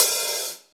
paiste hi hat1 half.wav